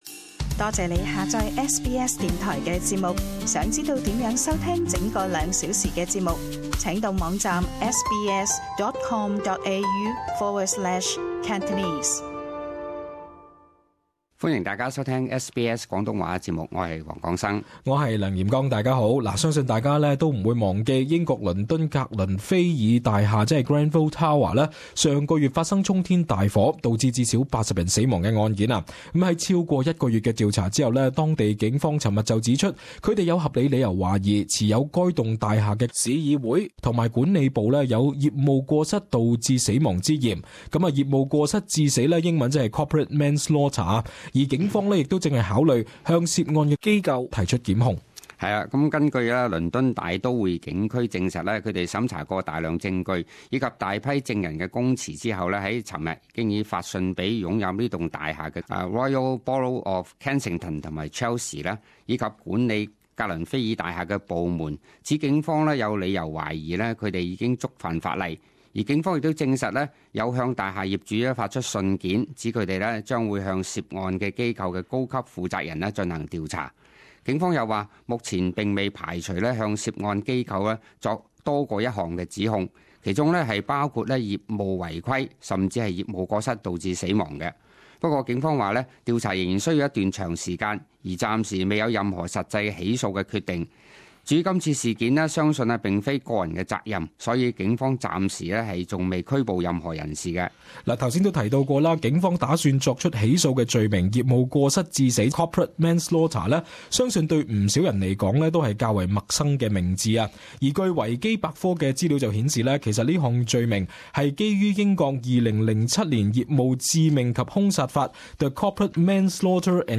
【時事報導】格倫菲爾大廈所在市議會或被控「業務過失致死」